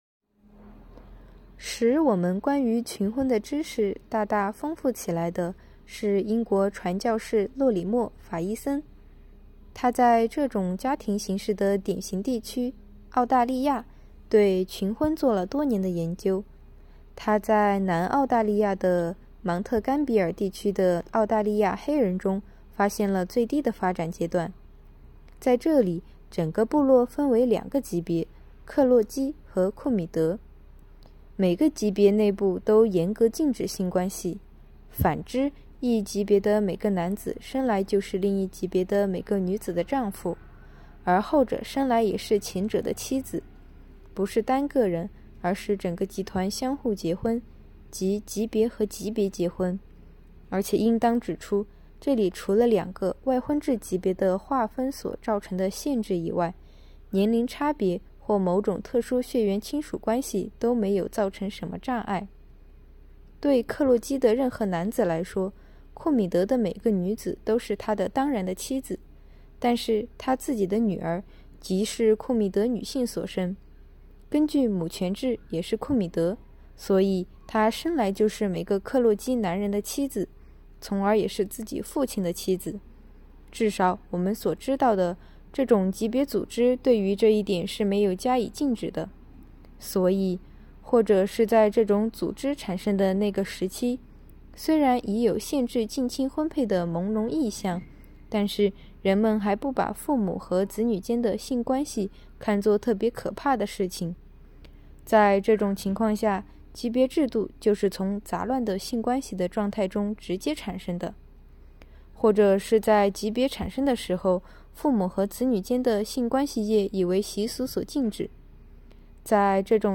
“读经典、悟原理”——2025年西华大学马克思主义经典著作研读会接力诵读（05期）